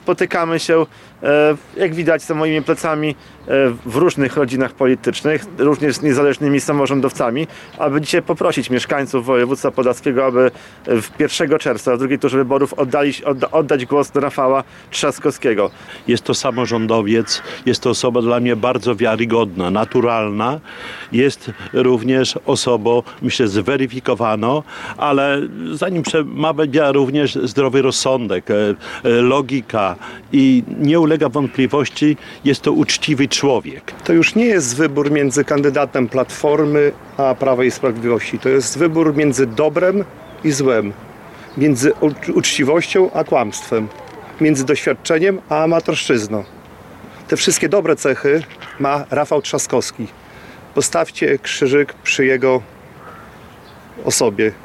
Do głosowania na Rafała Trzaskowskiego w II turze wyborów prezydenta Polski zachęcali w piątek (23.05.25) w Suwałkach przedstawiciele władz wojewódzkich na czele z marszałkiem województwa Łukaszem Prokorymem, parlamentarzyści Jacek Niedźwiedzki i Krzysztof Truskolaski oraz miejscowi samorządowcy, jak Czesław Renkiewicz, prezydent Suwałk. Podkreślali doświadczenie kandydata na arenie europejskiej, znajomość języków obcych i uczciwość.